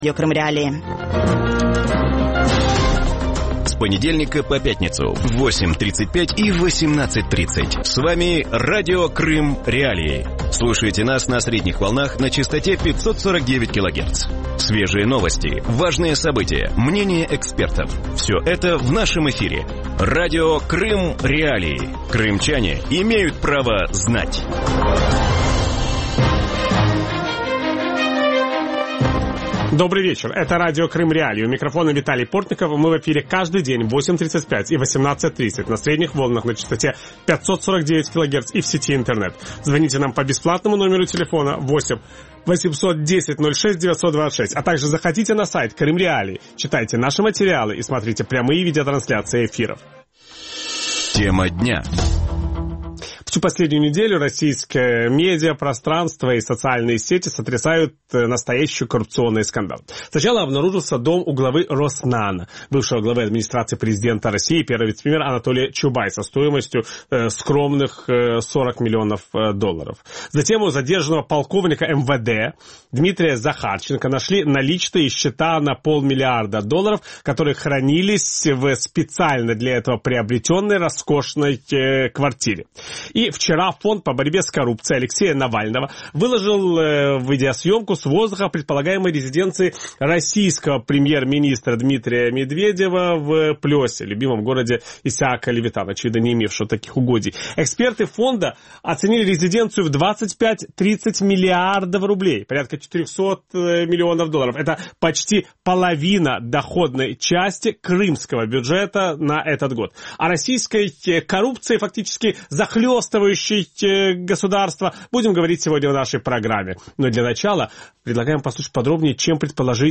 У вечірньому ефірі Радіо Крим.Реалії обговорюють можливий будинок російського прем'єр-міністра Дмитра Медведєва, про який повідомляє Фонд по боротьбі з корупцією, та інші корупційні скандали тижня. Звідки у російських чиновників розкішні маєтки, чи варто очікувати розслідувань і чому в останні дні в Росії один за іншим розгортаються корупційні скандали?
Ведучий: Віталій Портников.